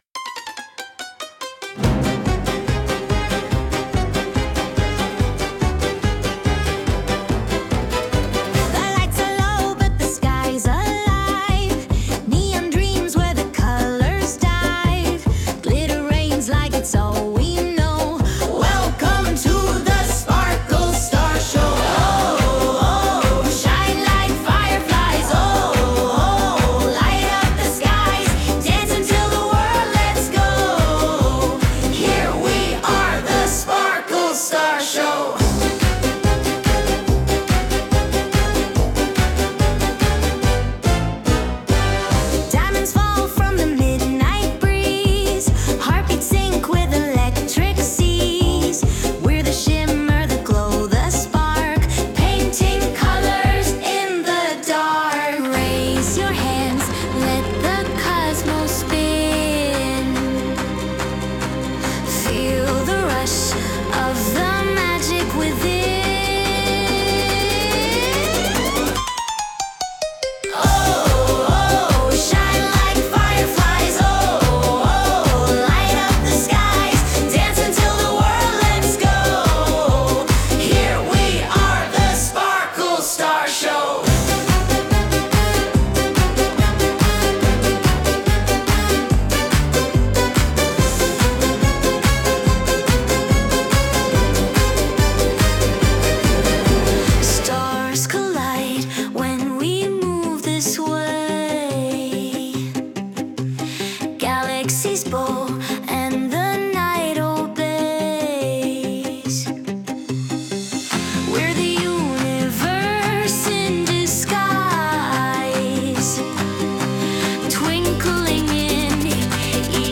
🌍 Shiny, fun, and full of groove!
キラキラと輝くステージをイメージした、ノリノリで楽しいショータイムミュージック！
明るくポップなリズムが、演技やダンスをより華やかに引き立てます。
笑顔いっぱいのパフォーマンスにぴったりの、ハッピーな一曲です✨